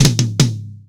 TOM     2C.wav